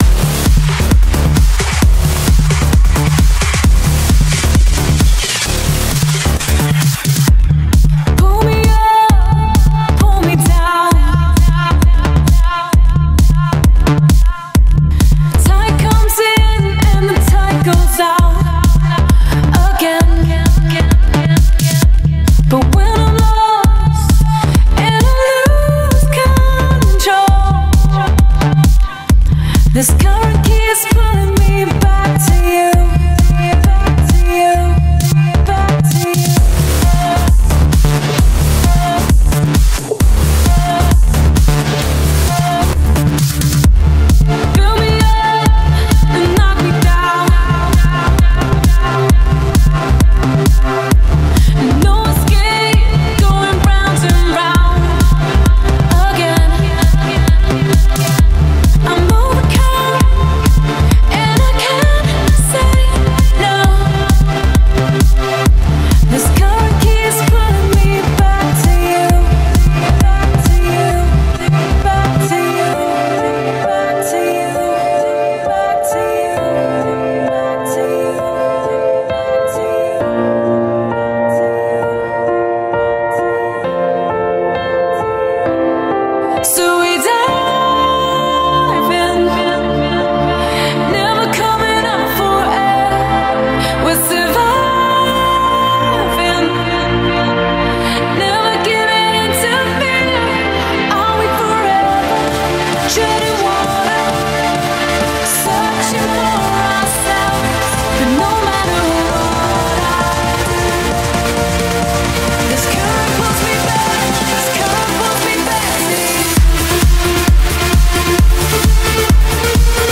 BPM62-133
MP3 QualityMusic Cut